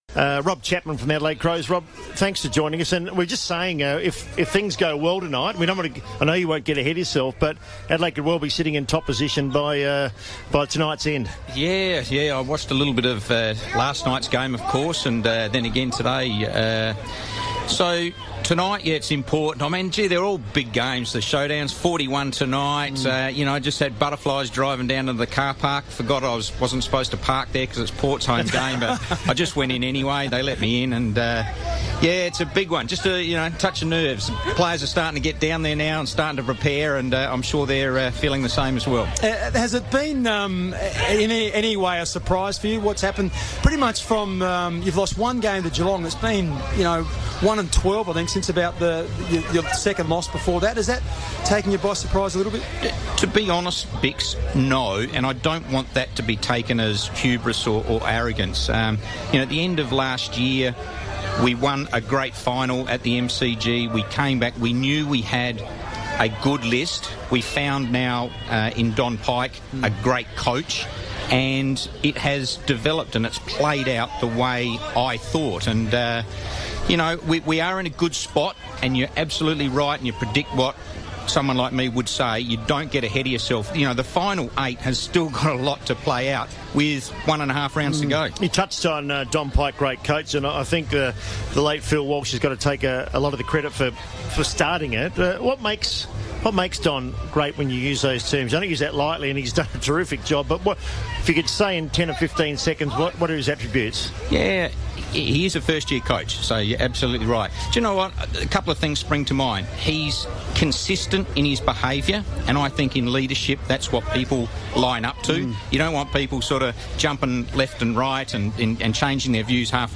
talks on FIVEaa ahead of Showdown XLI